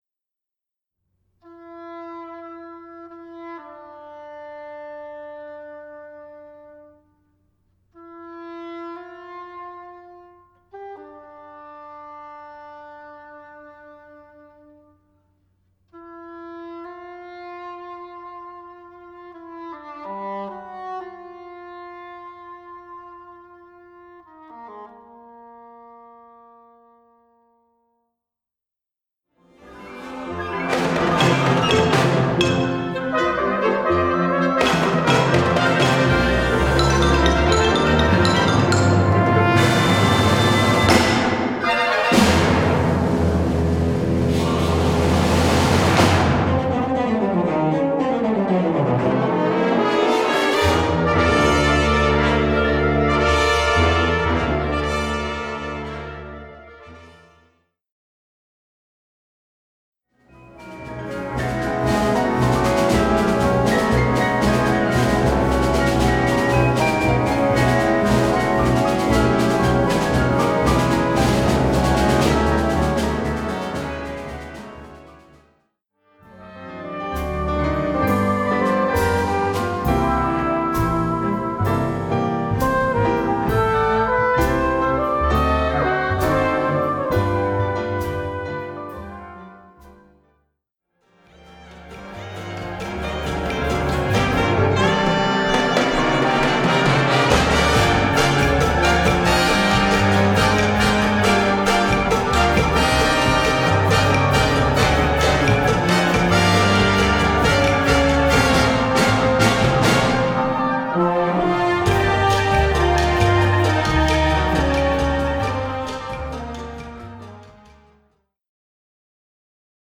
Catégorie Harmonie/Fanfare/Brass-band
Sous-catégorie Musique à vent contemporaine (1945-présent)
Instrumentation Ha (orchestre d'harmonie)
- Allegro : Le mouvement final est puissant et énergique.